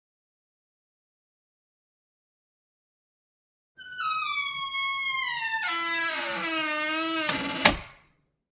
KDClbxkQkF8_grille-qui-souvre.mp3